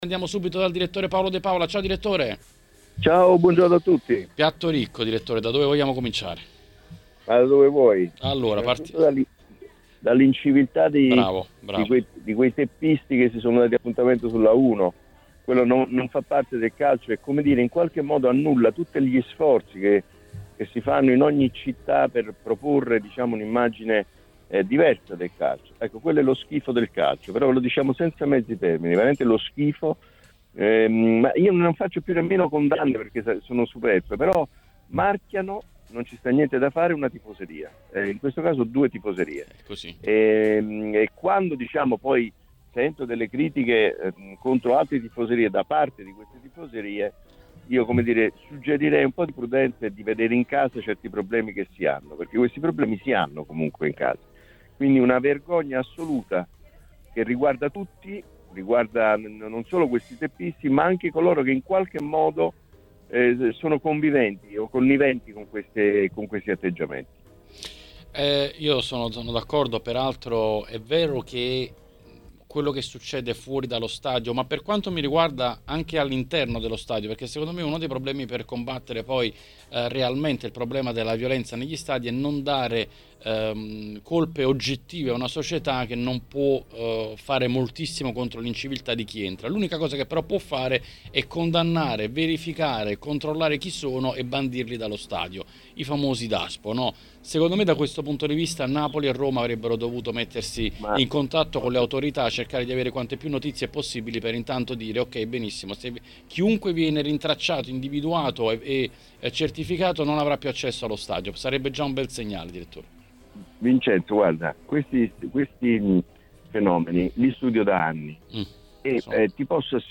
Editoriale